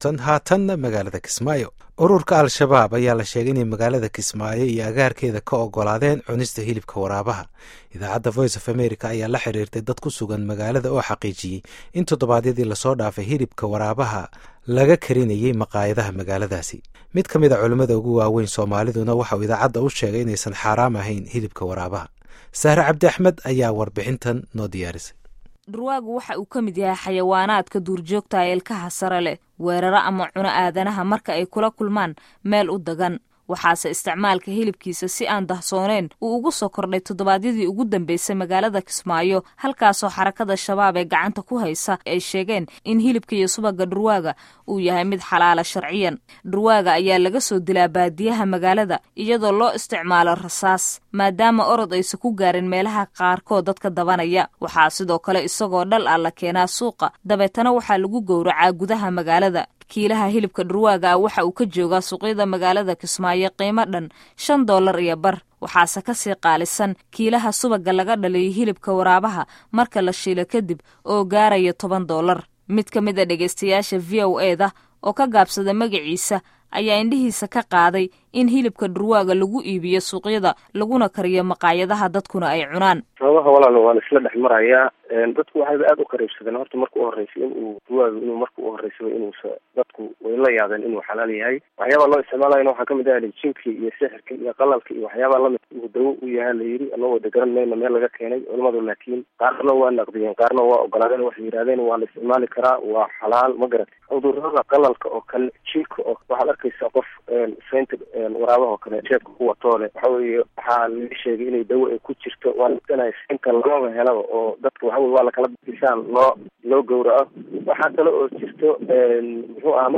Halkan ka dhageyso warbixin cunista Hilibka waraabaha